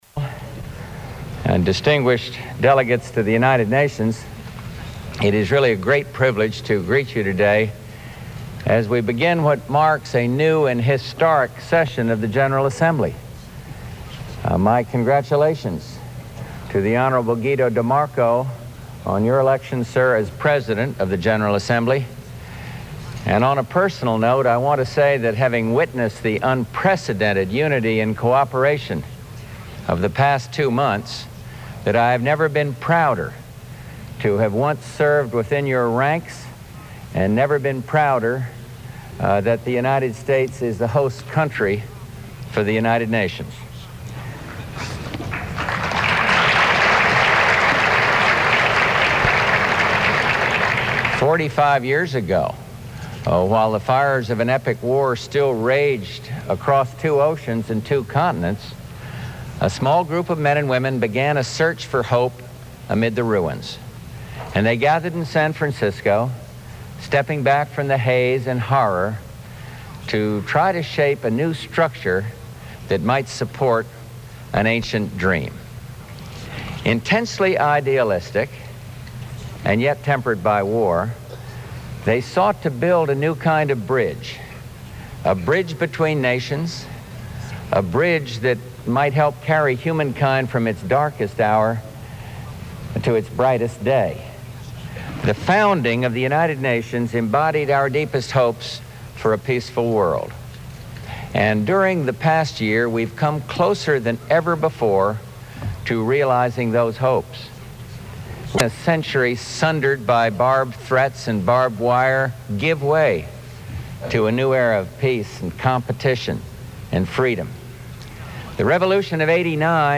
George Bush addresses the United Nations General Assembly, praising the work of that body, the current Soviet leadership, and the prompt United Nations response to the Iraqi invasion of Iraq
In Collections G. Robert Vincent Voice Library Collection Copyright Status No Copyright Date Published 1990 Speakers Bush, George, 1924-2018 Subjects United Nations. General Assembly Iraq-Kuwait Crisis (1990-1991) Diplomatic relations Soviet Union United States Material Type Sound recordings Language English Extent 00:23:00 Venue Note Broadcast on CNN, October 1, 1990.